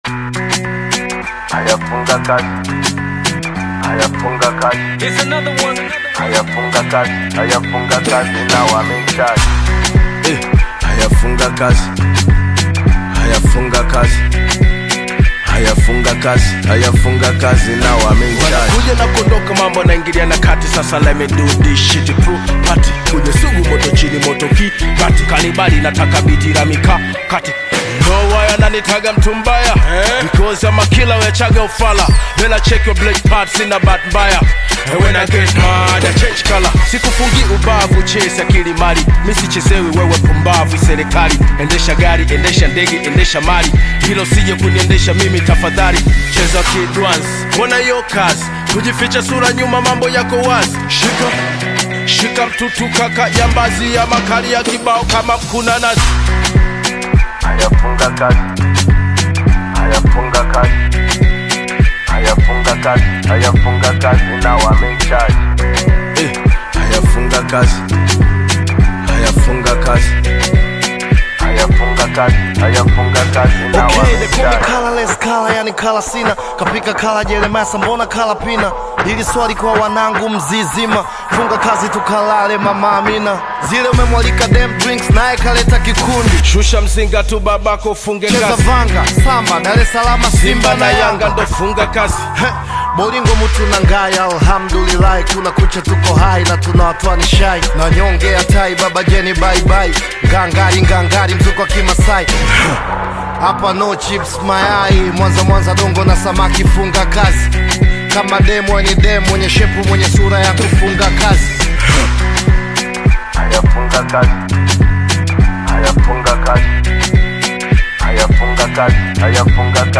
Tanzanian Bongo Flava
hip-hop song
African Music